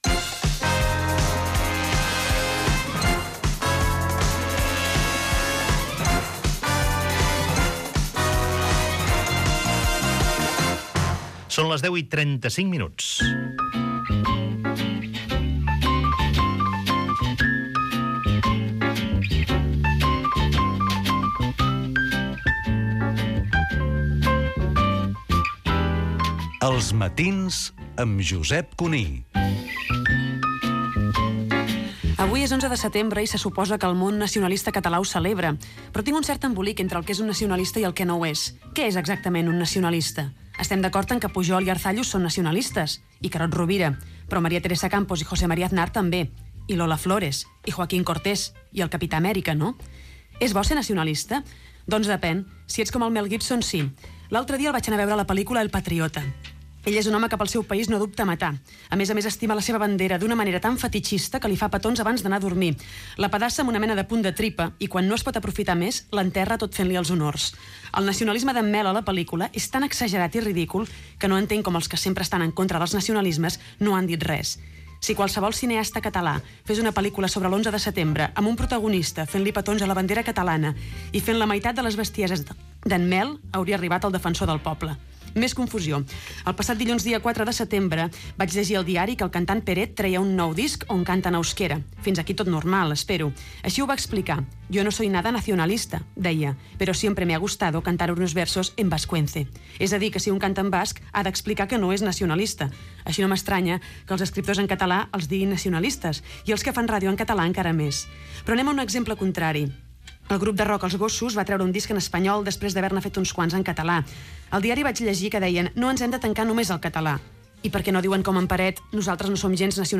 Hora, indicatiu i comentari de la periodista i escriptora Empar Moliner, col·laboradora del programa.
Info-entreteniment
FM